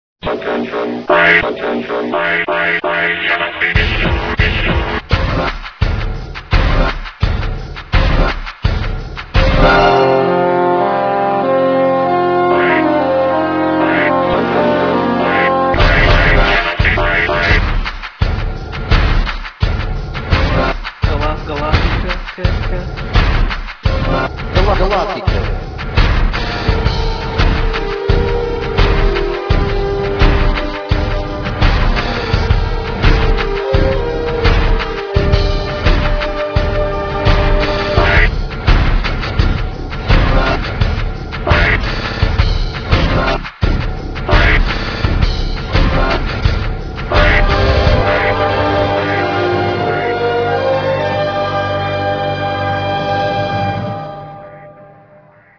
Pequena musica feita por mim - 109 Kb / Mono